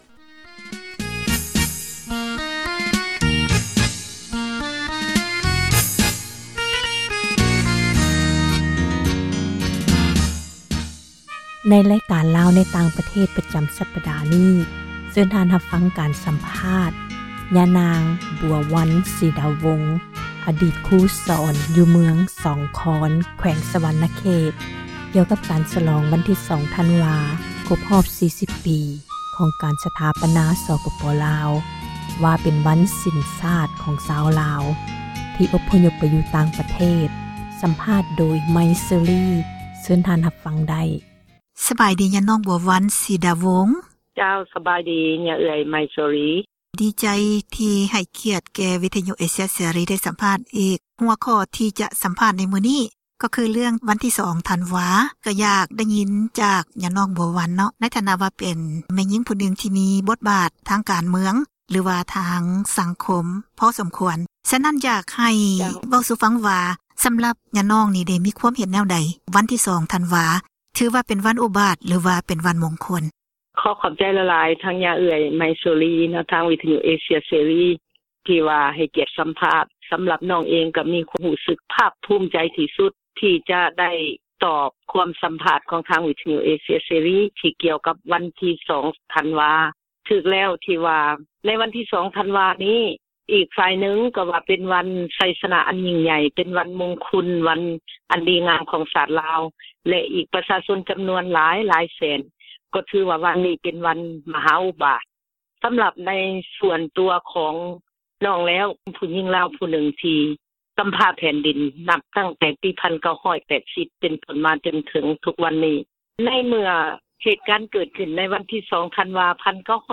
ສັມພາດຍານາງ